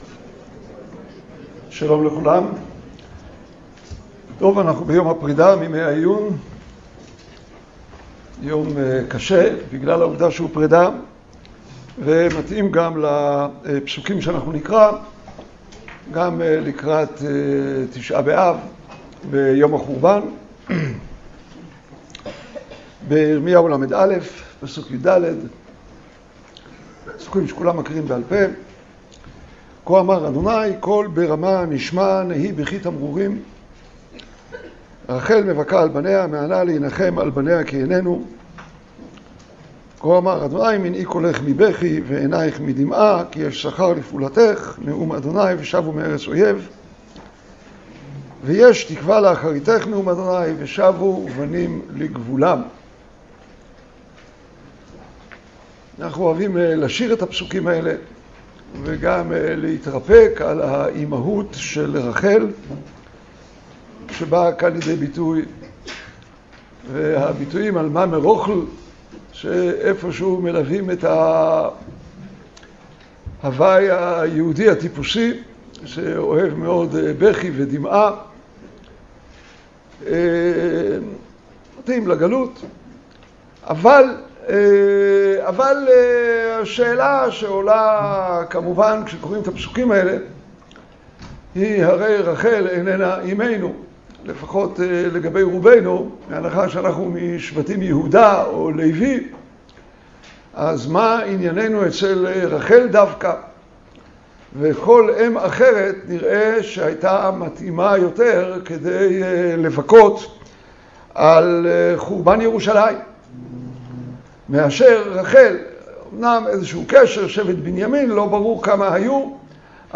השיעור באדיבות אתר התנ"ך וניתן במסגרת ימי העיון בתנ"ך של המכללה האקדמית הרצוג